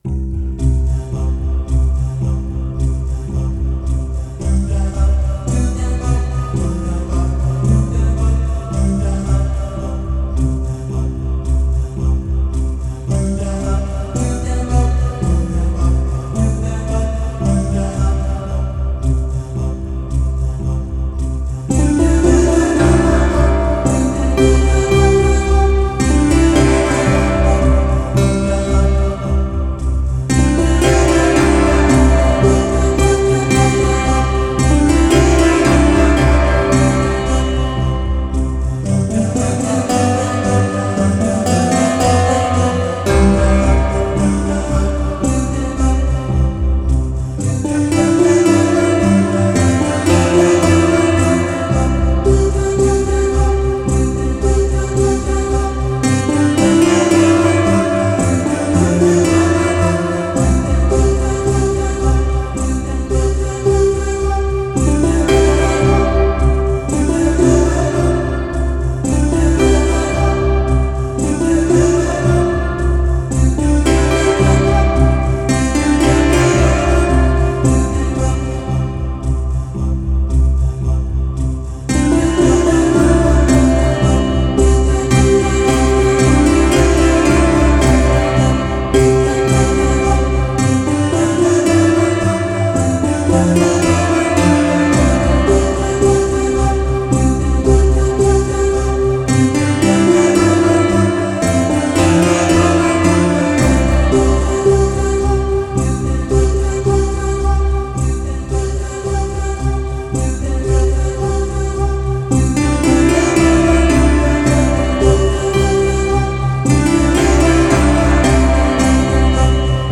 Spherical experimental Soundtrack with Voices.